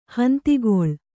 ♪ hantigoḷ